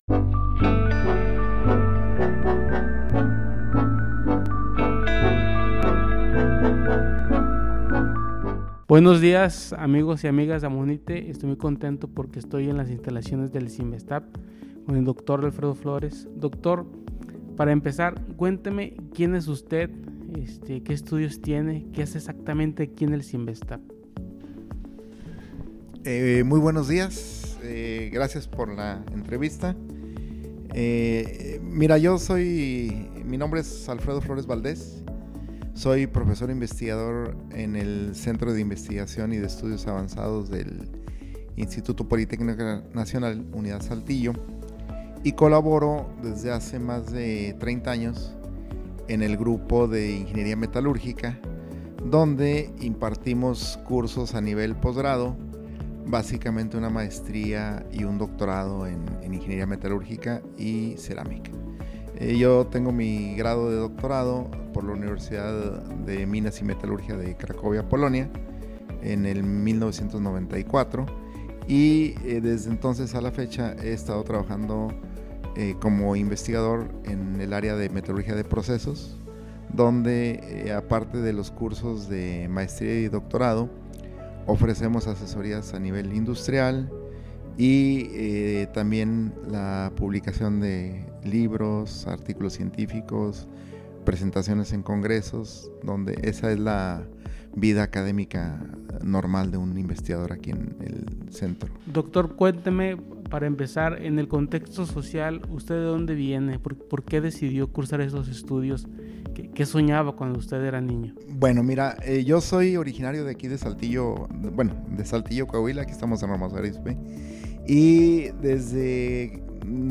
Entrevista y locución